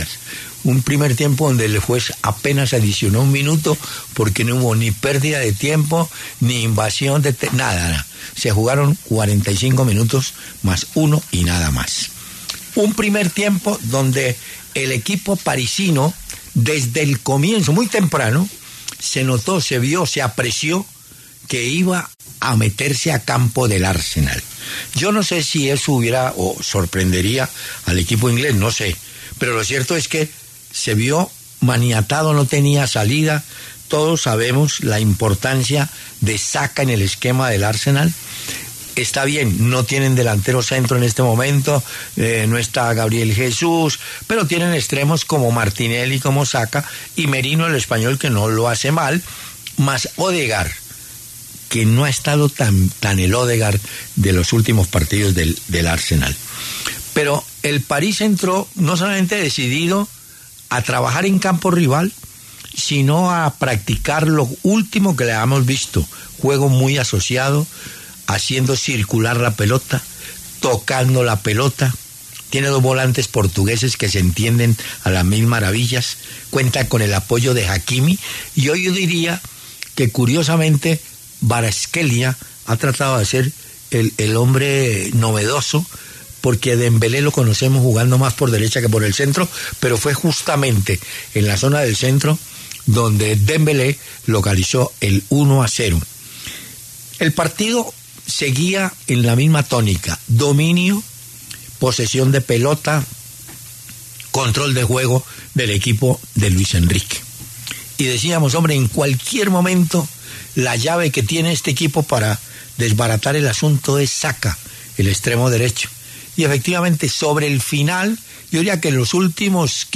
El reconocido periodista deportivo, Hernán Peláez, analizó en los micrófonos de W Radio el primer tiempo del duelo entre Arsenal y PSG por el partido de ida de las semifinales de la Champions League.